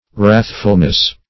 Wrath"ful*ness, n.